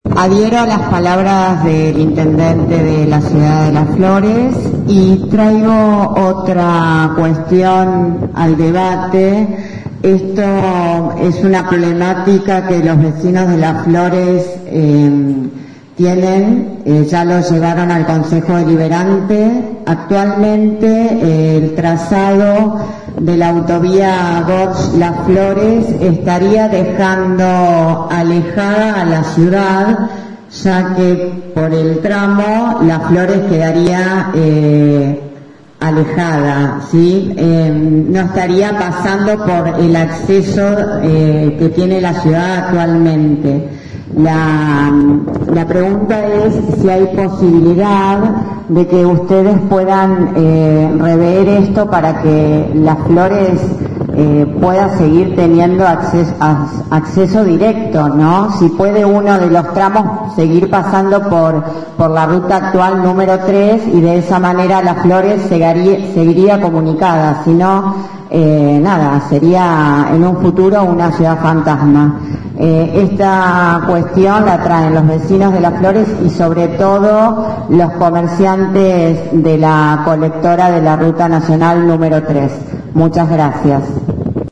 (incluye audios) Ayer miércoles en el Centro Cultural de la ciudad San Miguel del Monte se llevó a cabo la audiencia pública Zona Sur.
Concejal Luciana Cocola Ganum (Bloque LLA):